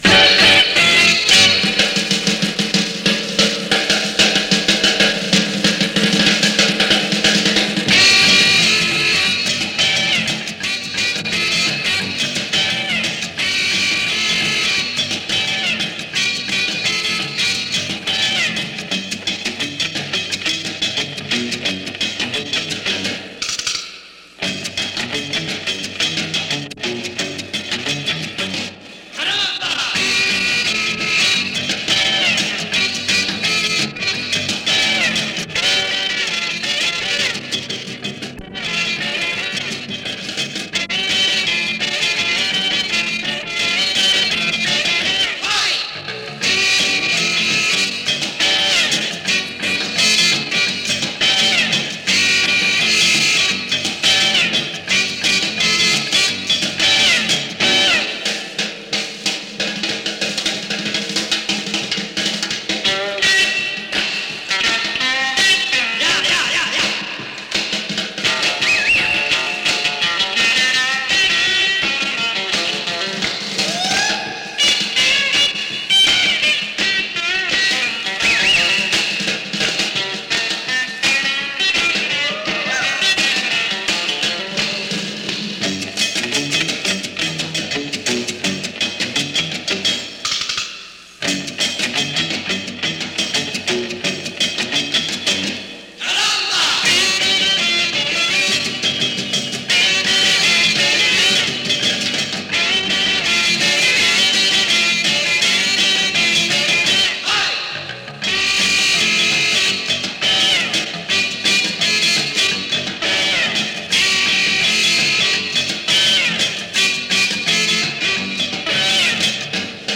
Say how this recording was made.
New-York-Radio-March-1959.mp3